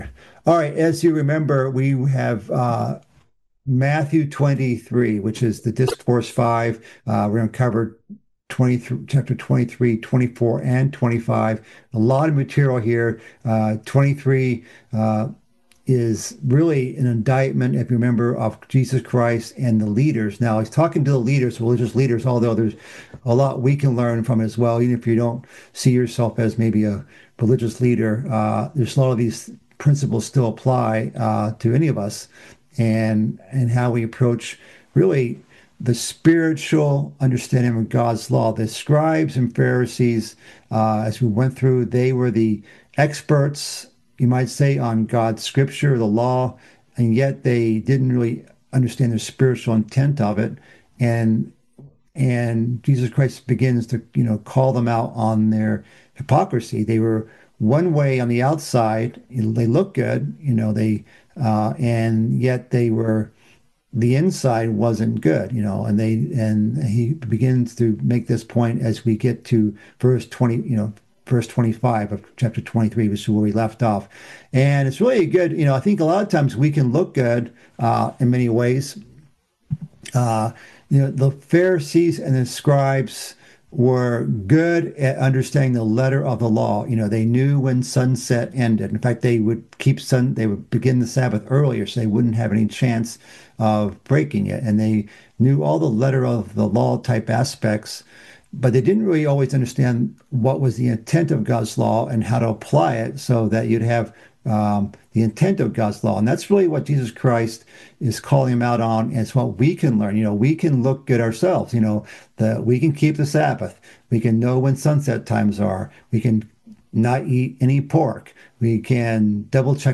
This is the fifth part of a mid-week Bible study series covering Christ's fifth discourse in the book of Matthew. It covers the remainder of the chapter listing woes to the scribes and Pharisees.